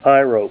Help on Name Pronunciation: Name Pronunciation: Pyrope
Say PYROPE Help on Synonym: Synonym: ICSD 71887   PDF 15-742   Rhodolite